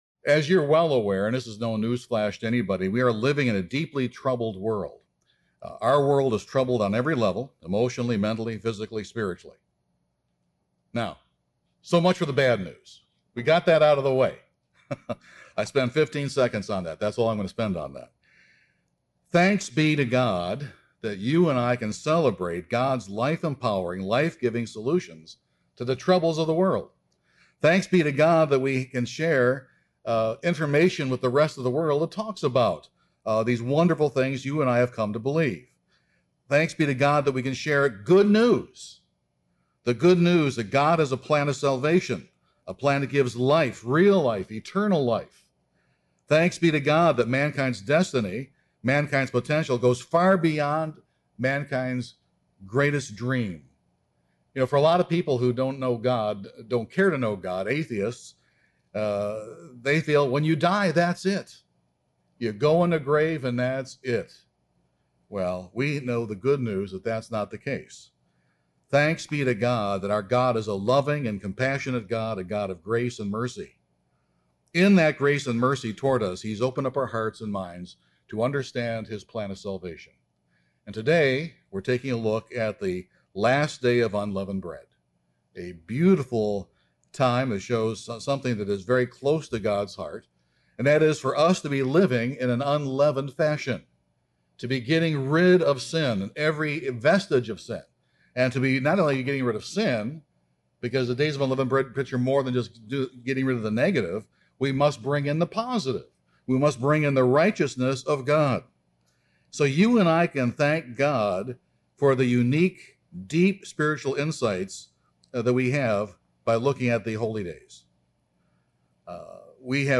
This sermon examines the workings of Godly Sorrow in that process.